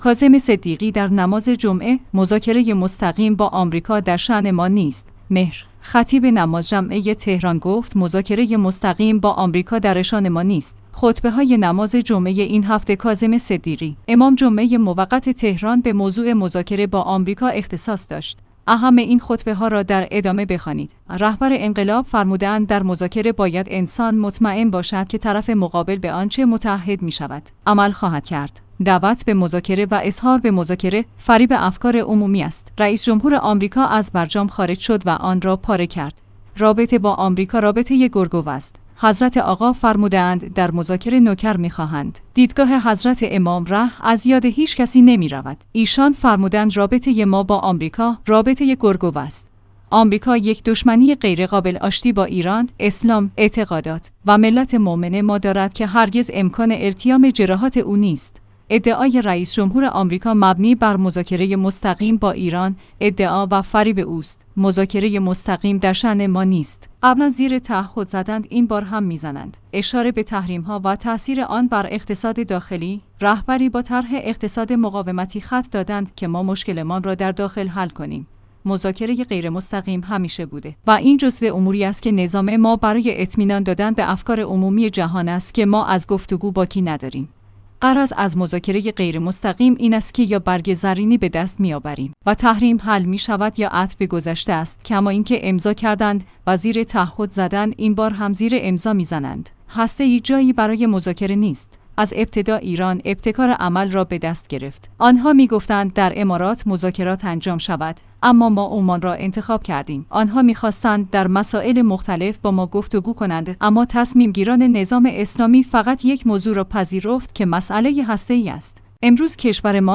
کاظم صدیقی در نماز جمعه: مذاکره مستقیم با آمریکا در شأن ما نیست